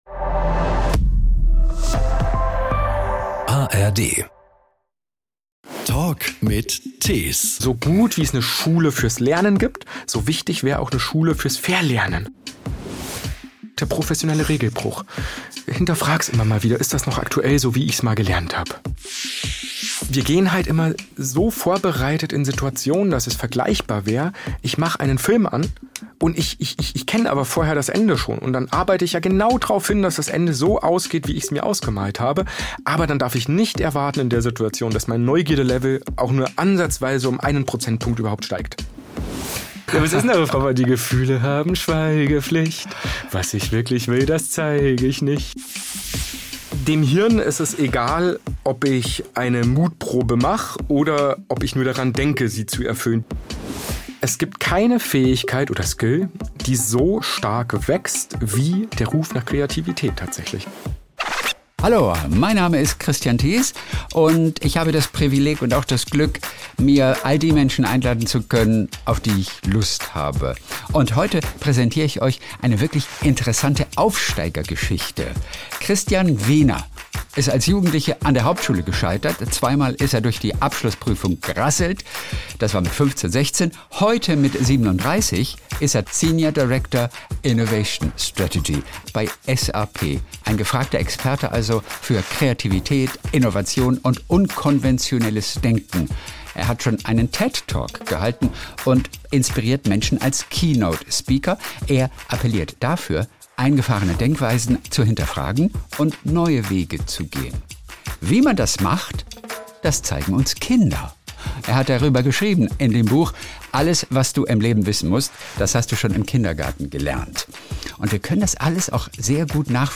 Ein wunderbares, inspirierendes – und oft auch amüsantes – Gespräch mit einem, bei dem alles ganz anders kam, als er selbst es jemals erwartet hätte.